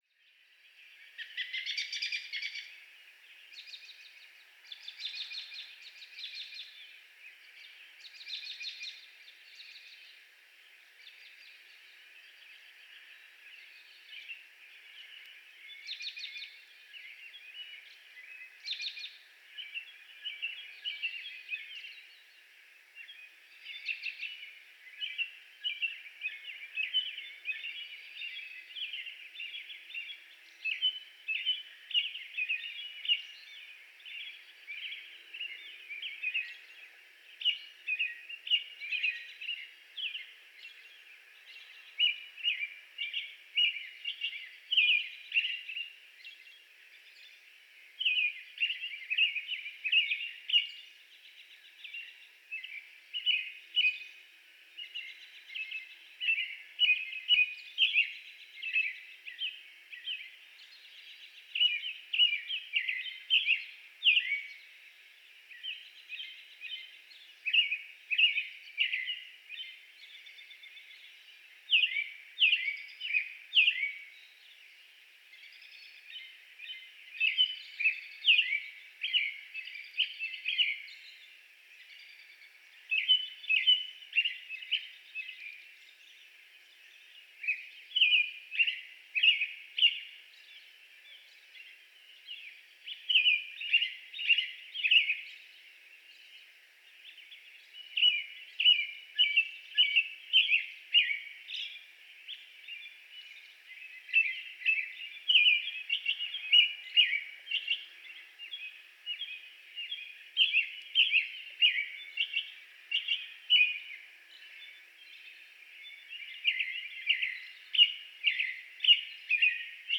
American Robin Dawn Song
Recorded in my backyard on April 11, 2024. This recording started about 5:15 am, and includes his entire singing performance in this spot.
American_Robin_Dawn_Song.mp3